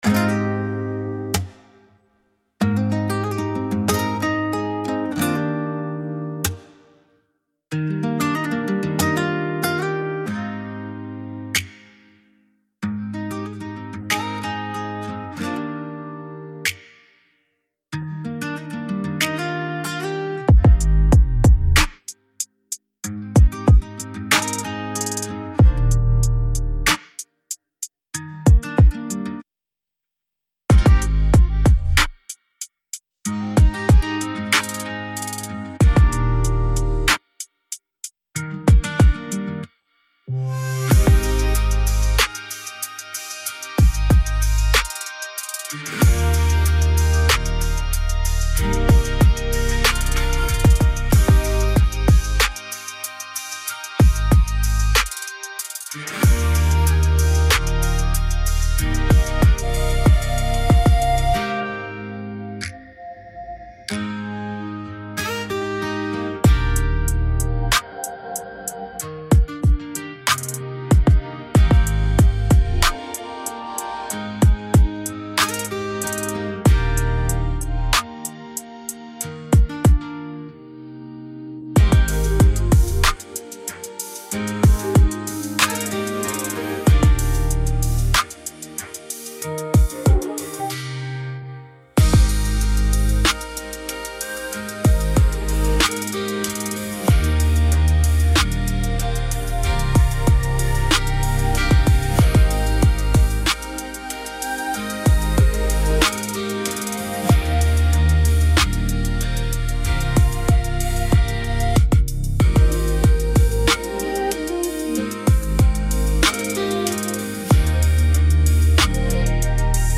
again-falling-for-you-instrumental-mp3-334ccbef